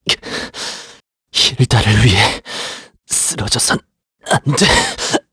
Siegfried-Vox_Dead_kr_b.wav